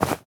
foley_cloth_light_fast_movement_13.wav